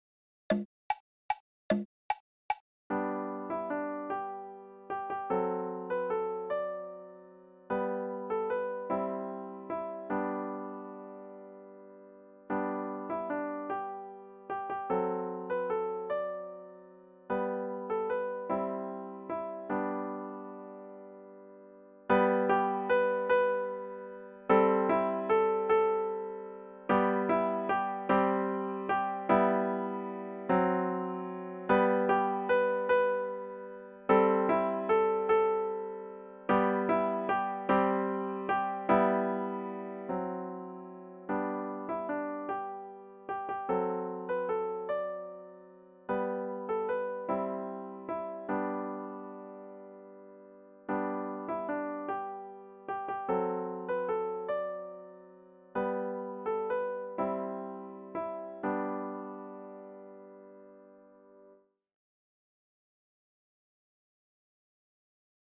Free Piano Music!